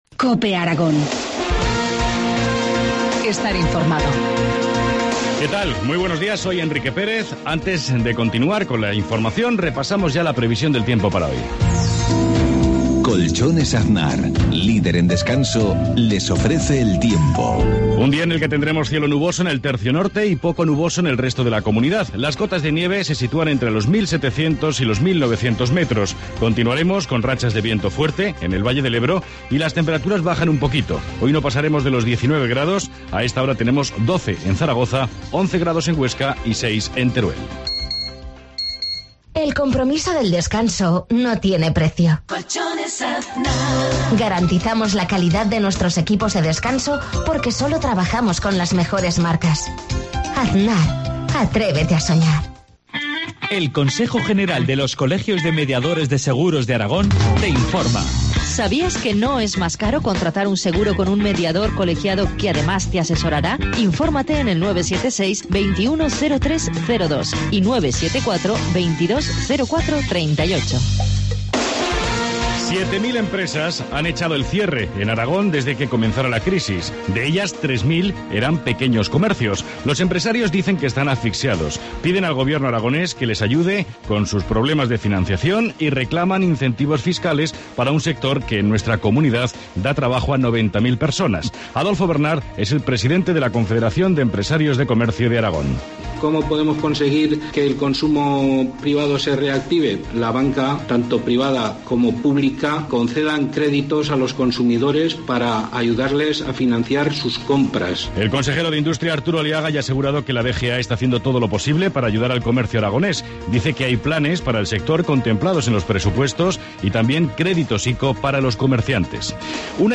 Informativo matinal, viernes 31 mayo 7,50 horas